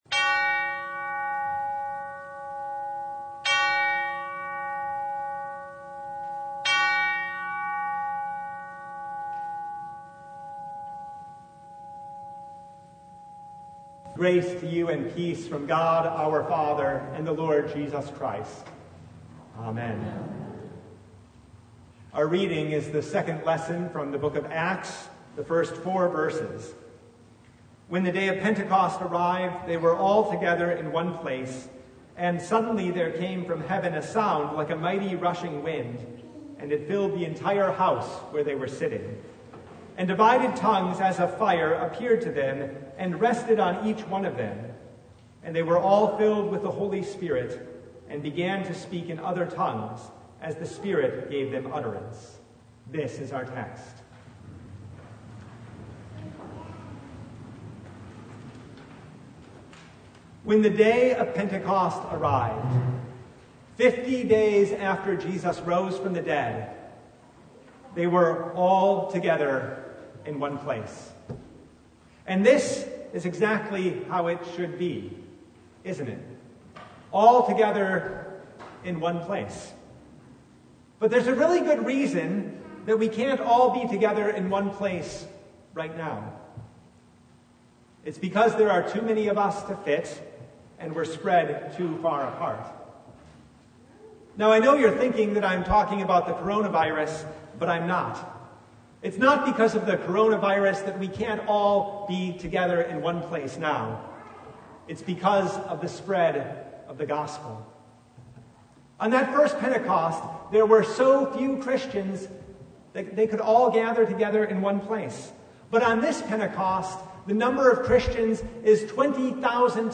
Service Type: The Feast of Pentecost